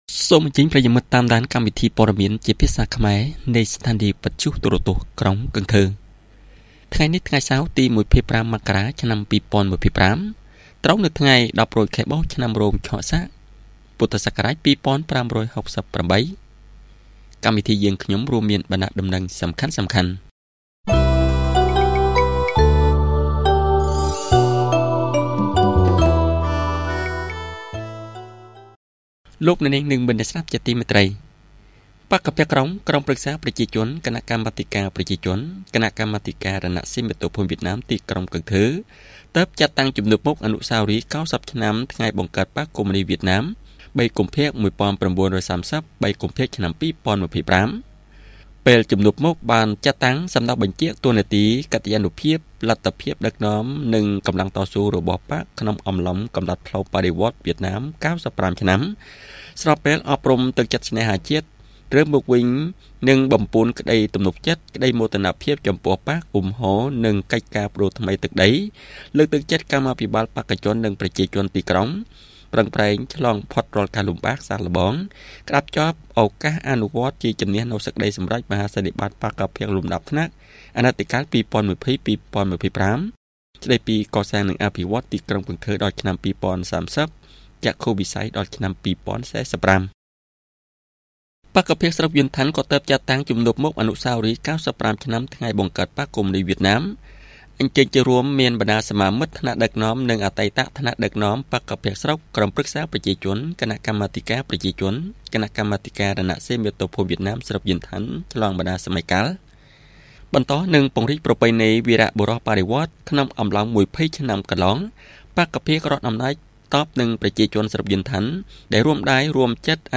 Bản tin tiếng Khmer tối 25/1/2025